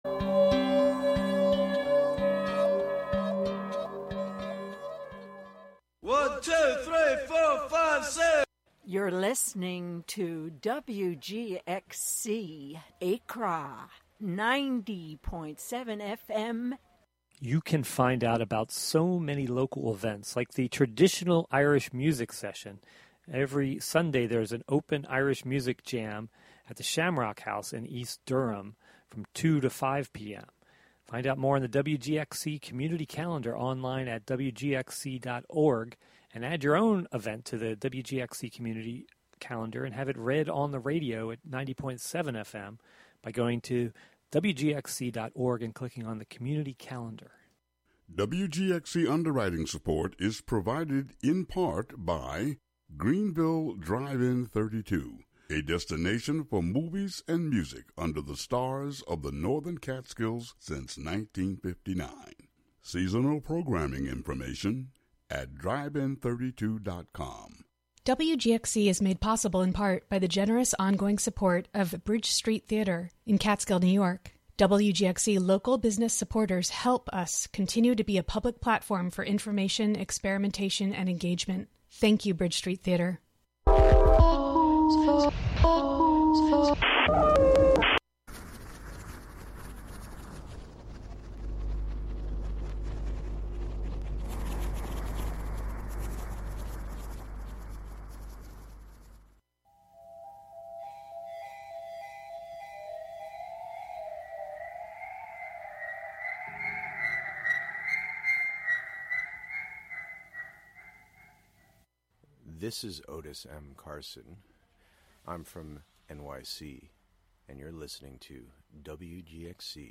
Every episode features an interview with local bird people, plus a freeform mix of sound made by birds and humans inspired by birds, and “Birds of Wave Farm,” a field recording journal from Wave Farm, in Acra, New York.